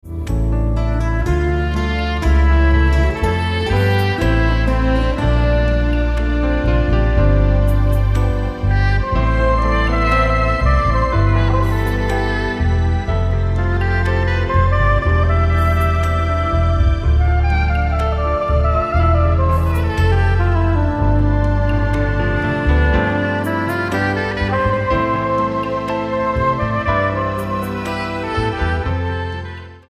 STYLE: Roots/Acoustic